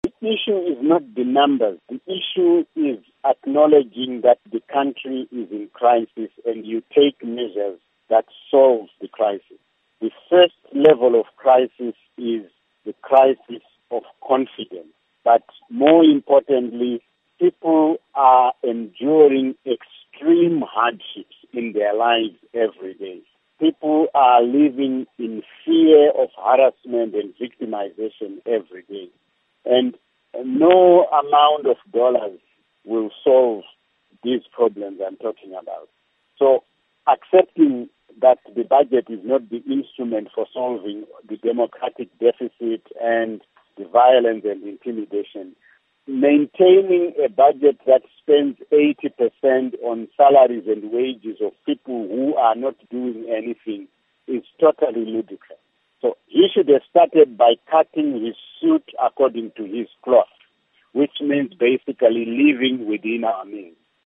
Interview With Simba Makoni on the the Government's 2016 Budget Proposal